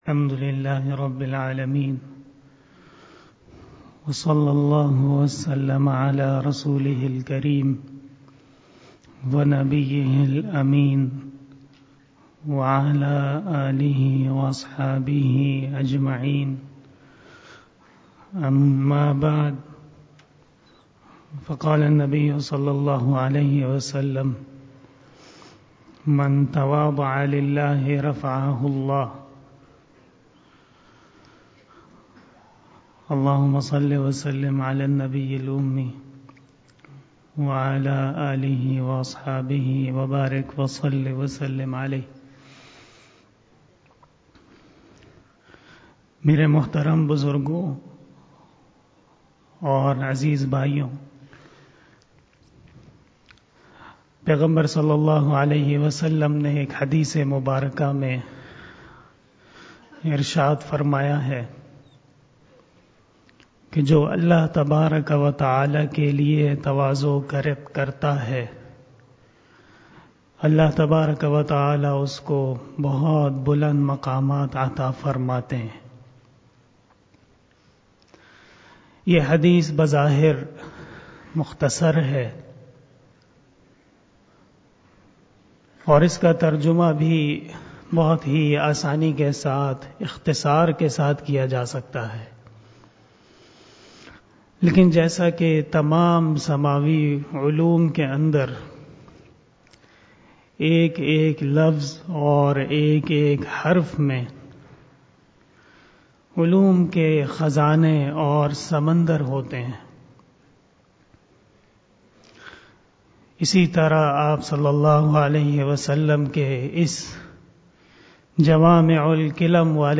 Bayan
10:00 PM 617 Shab-e-Jummah 2020 بیان شب جمعہ 07 جمادی الأول 1441 ھجری 02 جنوری 2020 ء ۔